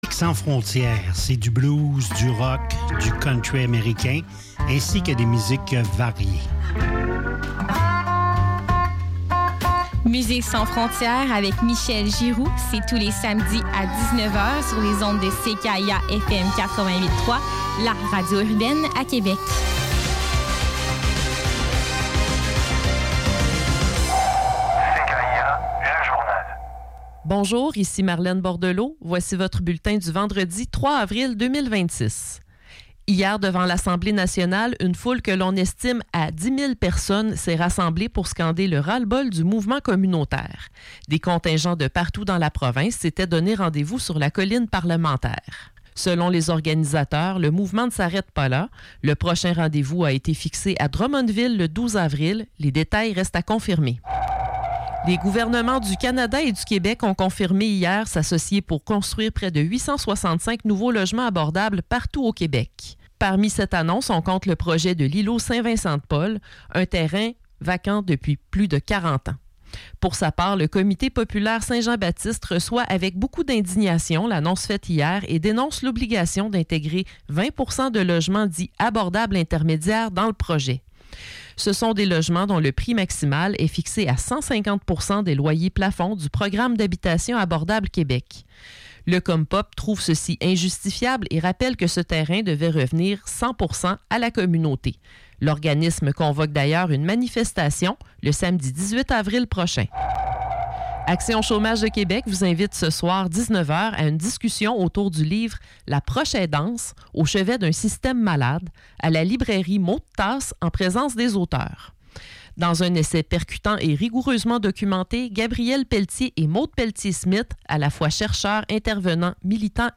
Revue de presse liée à l’actualité, aux changements climatiques et à la surconsommation, principalement dans Le Devoir.